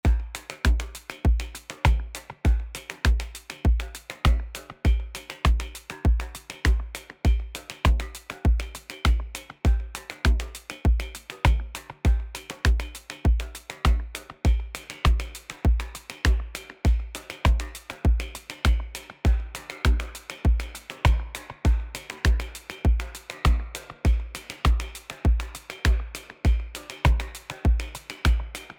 Elektron Gear Machinedrum